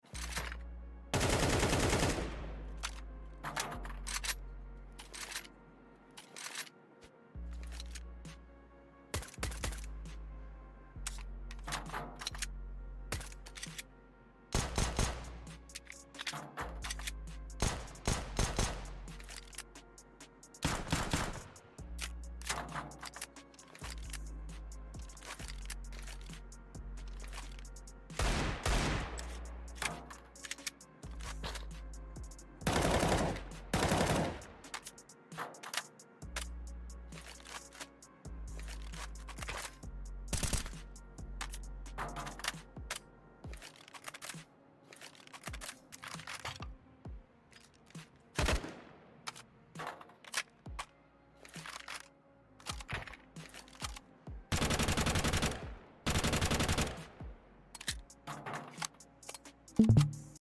Absolutely new animations and sounds on all guns CS2.